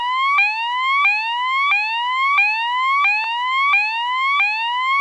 855T 传感器式声音模块
我们的声音模块最多可提供 15 种音调，并且音量、频率和速度均可调。
上升音调
tone60_ascending.wav